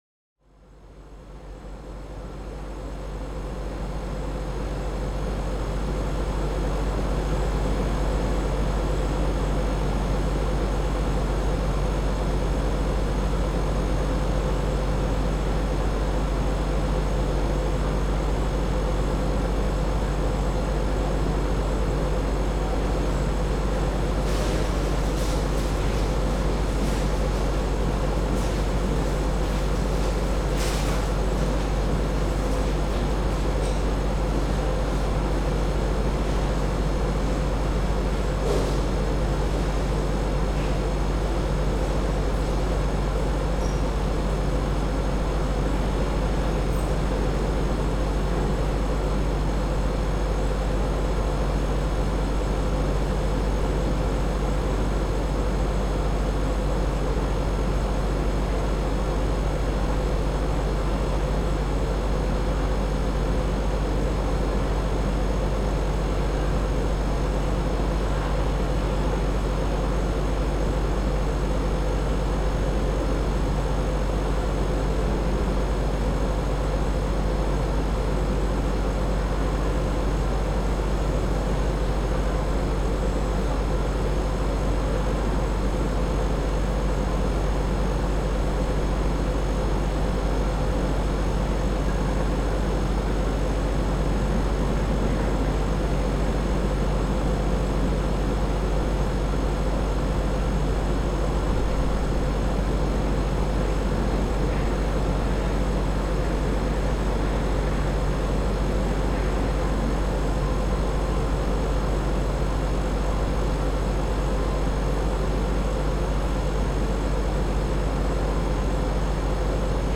AMB_Scene01_Ambience_LS.ogg